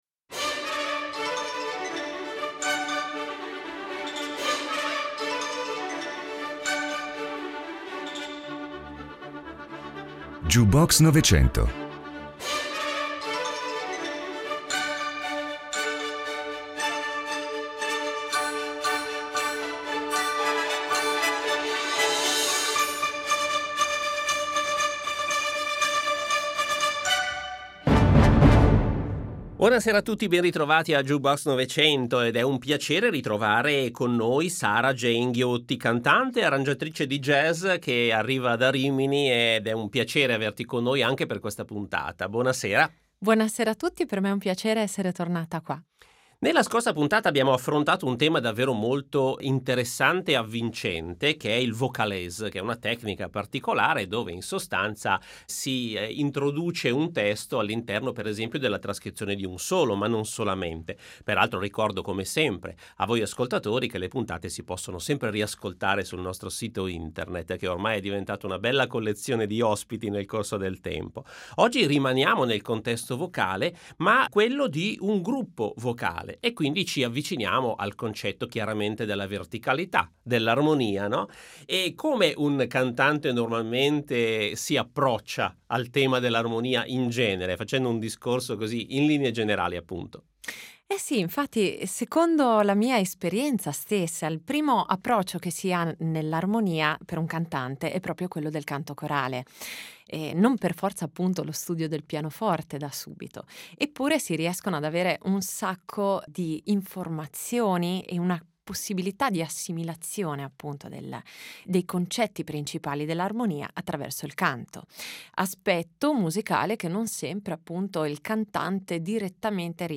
Vocal Group. Imparare a cantare assieme, a coesistere in armonia attraverso l’armonia, è una lezione importantissima e senza tempo, giacché, in ambito didattico, da sempre si prevede tale esperienza formativa.